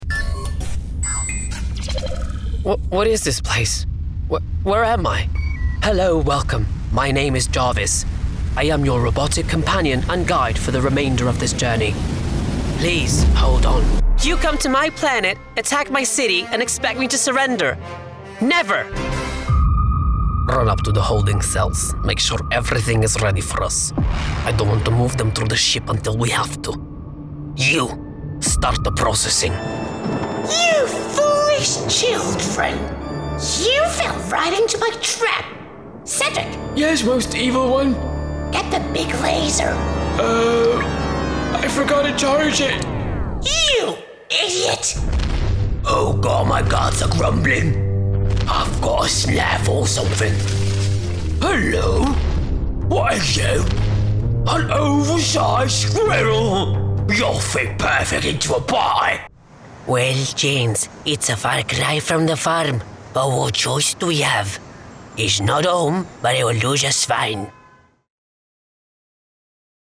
Sprechprobe: Sonstiges (Muttersprache):
I have a home studio setup as well: Nova Black Condenser Mic, AIR 192|4 Interface, Computer Specs: 10.15.5 MAC OS X, Recording Software: Logic Pro X
Character Voices.mp3